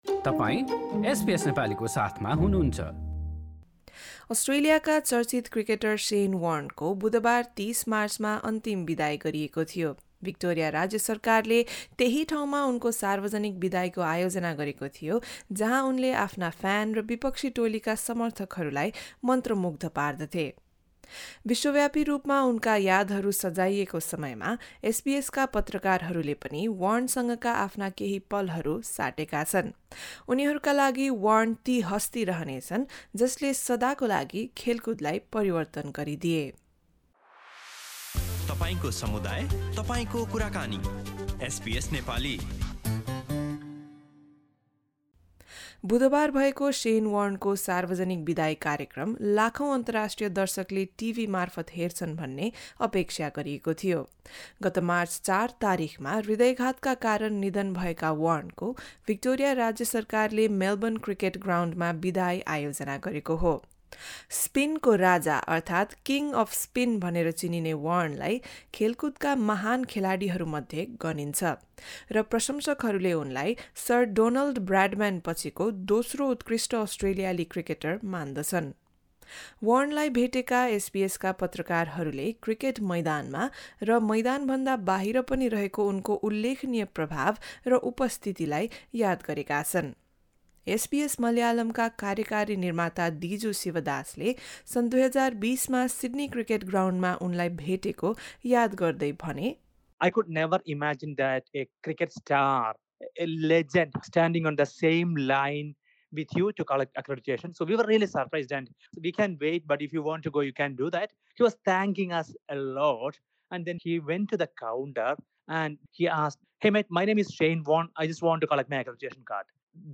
अस्ट्रेलियाका चर्चित क्रिकेटर शेन वार्नको हिजो बुधबार ३० मार्चमा अन्तिम बिदाइ गरिएको थियो। यसै मौकामा एसबीएसका पत्रकारहरूले वार्नसँगका आफ्ना केही पलहरू साटेका छन्।